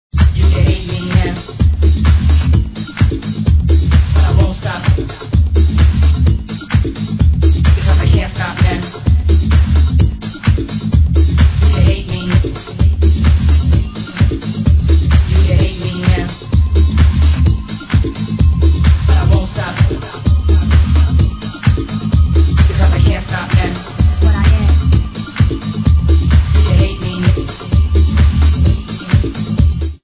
great basslines !